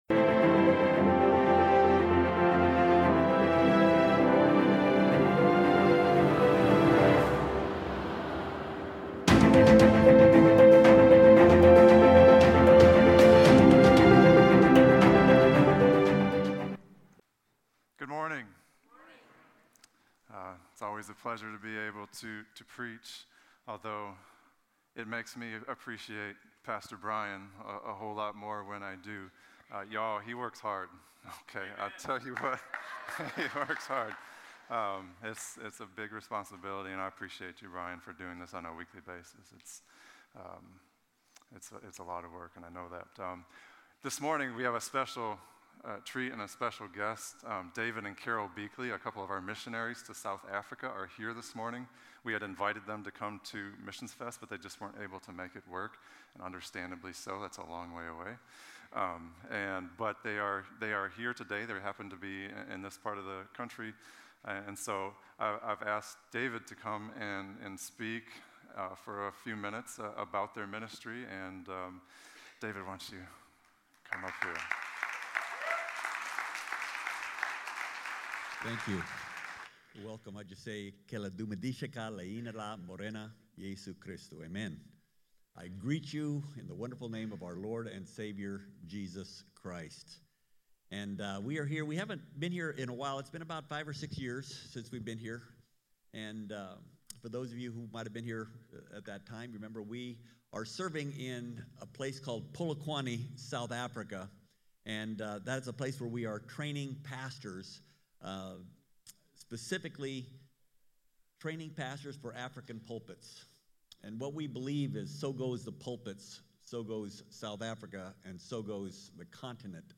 Message
A message from the series "To Seek and To Save."